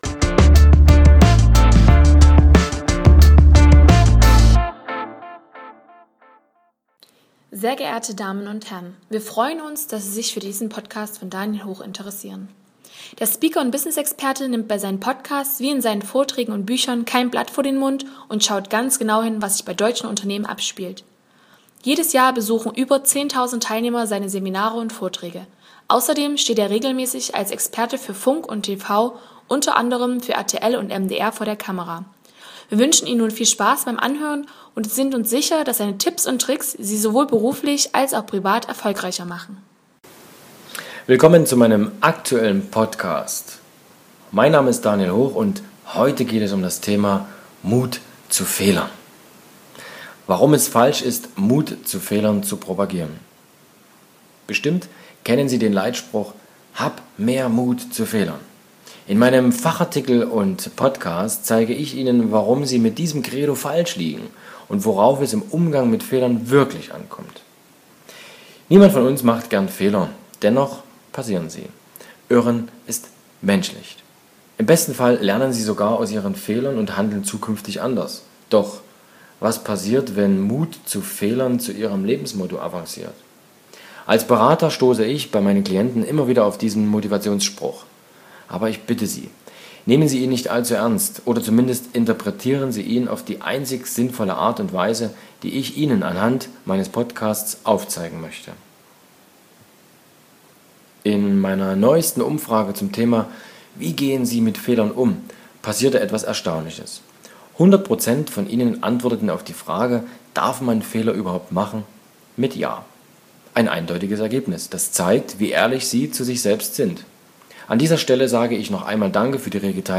Vortragsmitschnitte